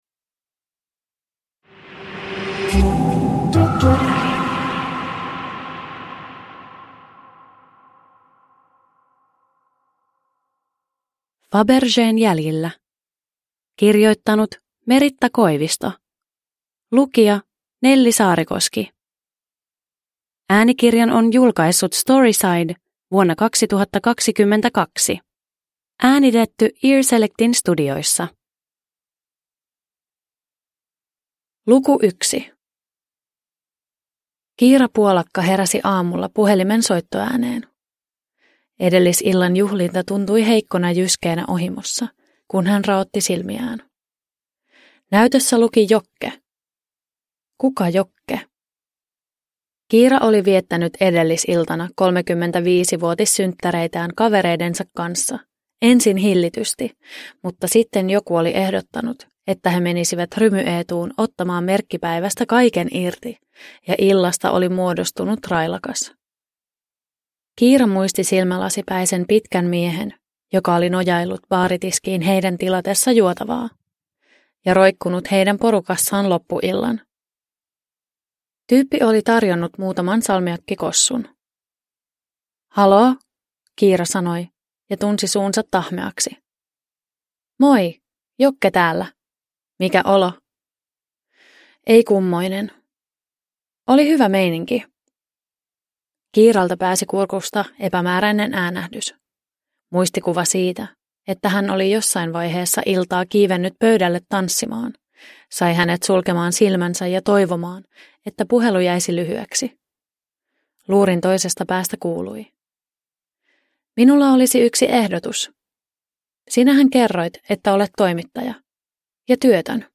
Fabergén jäljillä – Ljudbok – Laddas ner